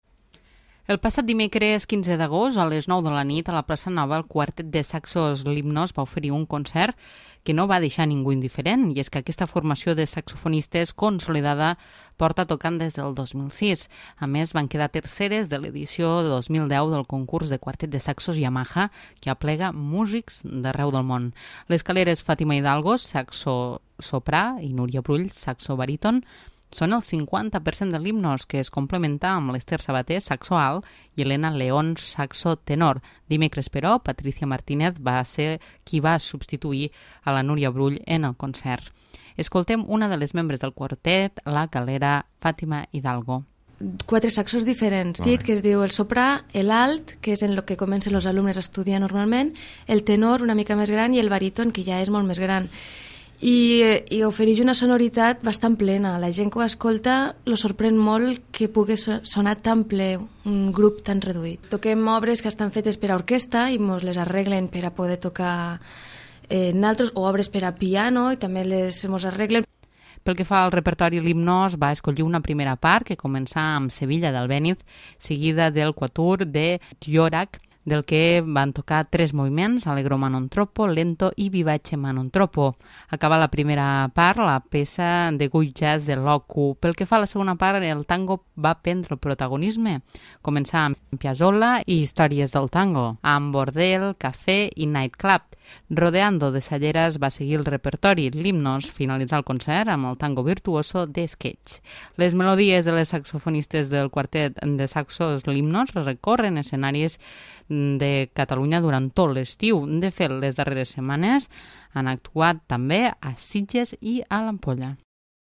a les nou de la nit, a la plaça nova
saxo soprà
saxo baríton
saxo tenor.
Pel que fa a la segona part, el tango va prendre el protagonisme.